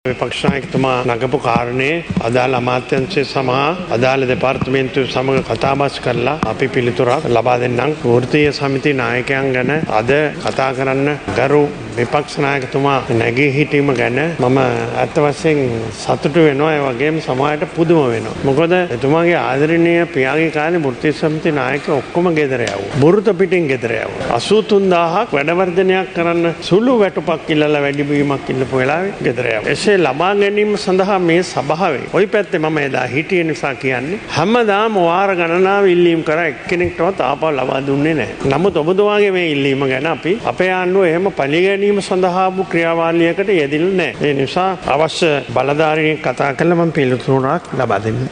එහිදී විපක්ෂනායකවරයා සහ අග්‍රාමාතවරයා මෙලෙස අදහස් පළ කළා.